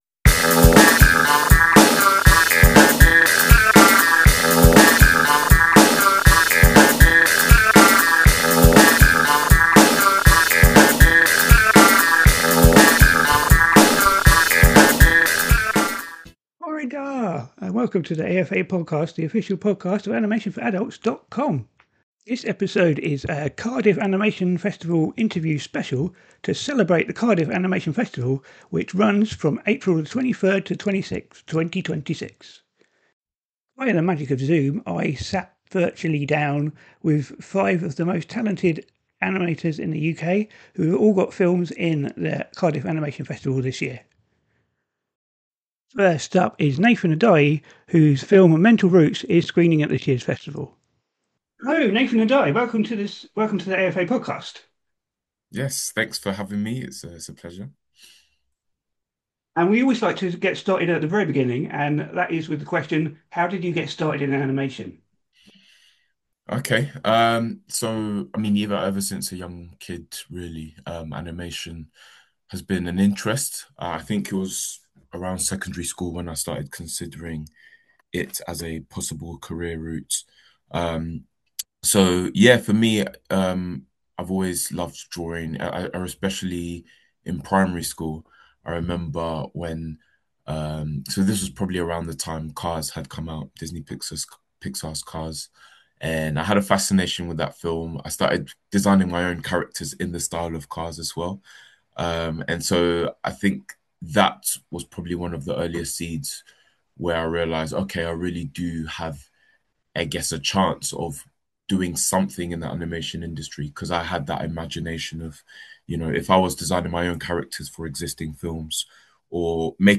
The AFA Podcast: Cardiff Animation Festival Interview Special
Chatting with five filmmakers from this year's Cardiff Animation Festival.